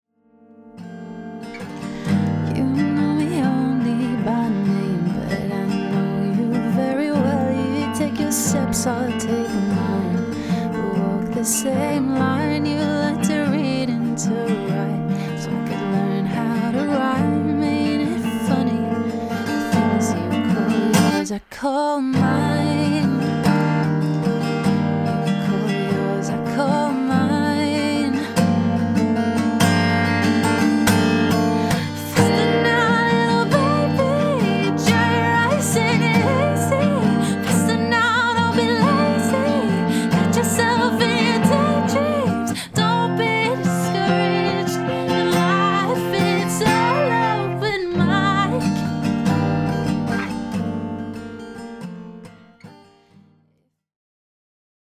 LIVE DEMO 6